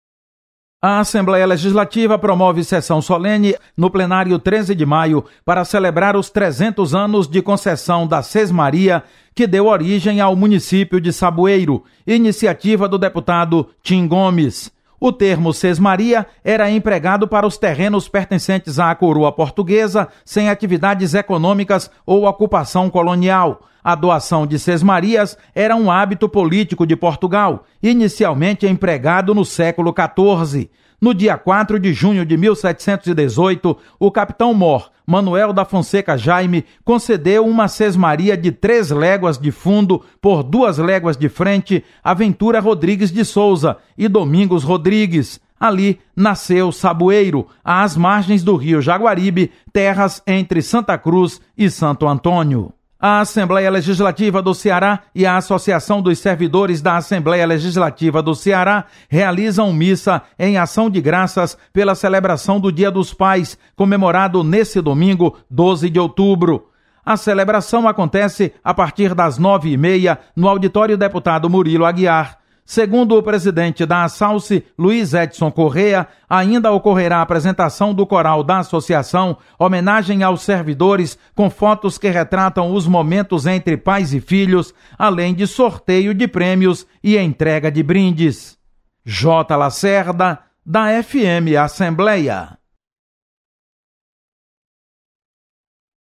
Acompanhe as atividades da Assembleia Legislativa para esta segunda-feira (13/08). Repórter